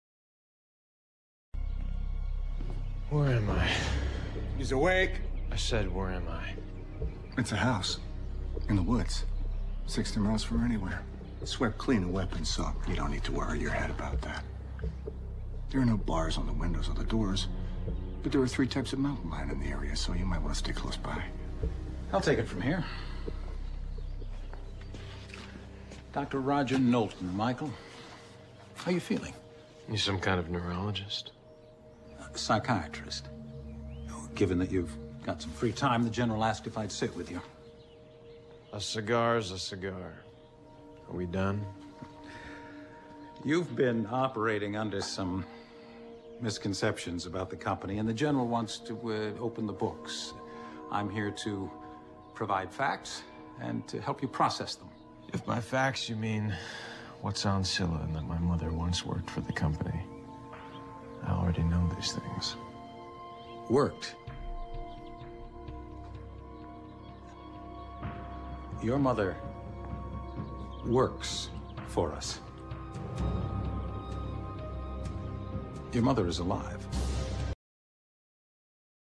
在线英语听力室影视剧中的职场美语 第108期:开诚布公的听力文件下载,《影视中的职场美语》收录了工作沟通，办公室生活，商务贸易等方面的情景对话。